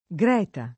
vai all'elenco alfabetico delle voci ingrandisci il carattere 100% rimpicciolisci il carattere stampa invia tramite posta elettronica codividi su Facebook Greta [ g r $ ta ; ingl. g r & itë o g r $ të o g r $ itë ; sved. g r % eta ] pers. f. (= Margherita)